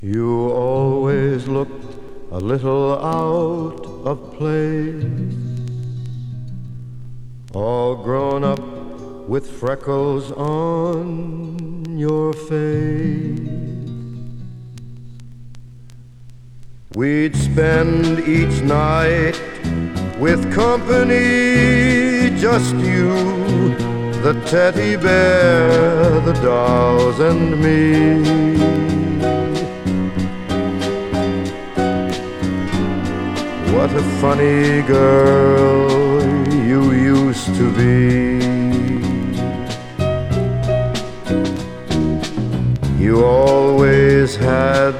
Jazz, Pop, Vocal, Ballad　USA　12inchレコード　33rpm　Stereo